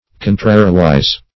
Contrariwise \Con"tra*ri*wise\ (? or ?), adv.